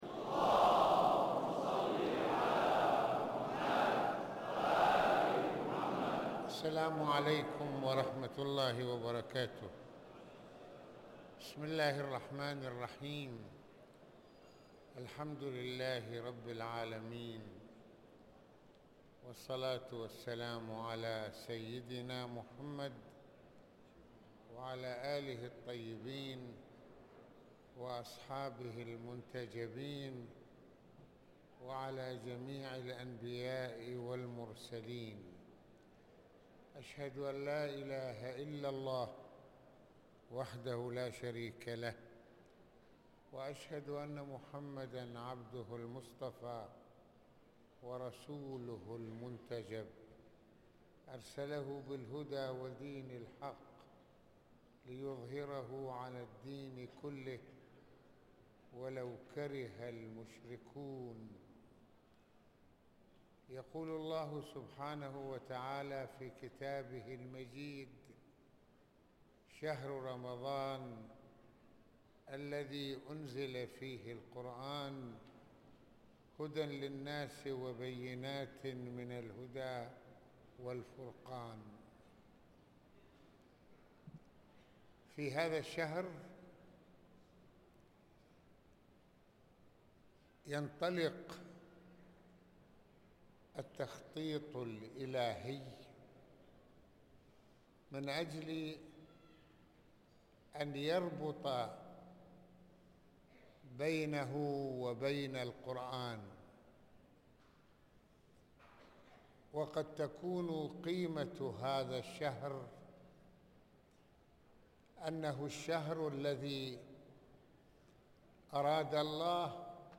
الصوم وكتاب الهداية | محاضرات رمضانية